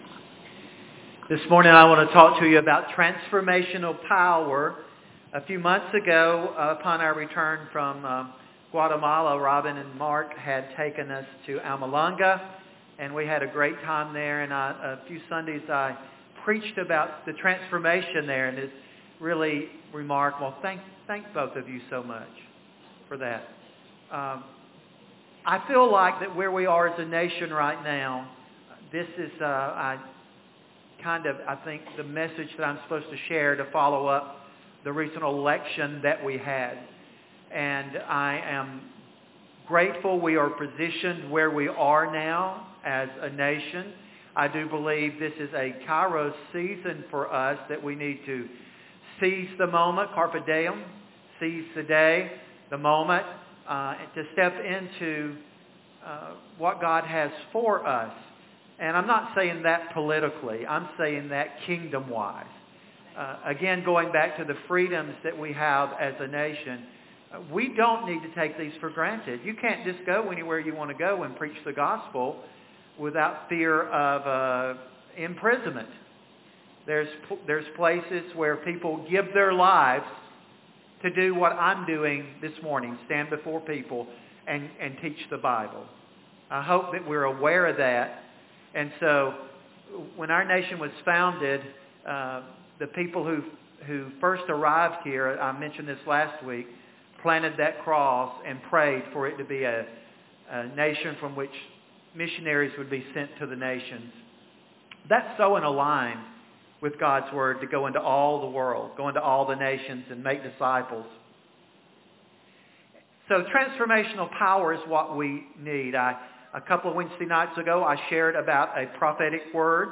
Sermons | Grace House